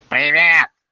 Привет противным голосом (звук)
• Категория: Привет(приветствие)
• Качество: Высокое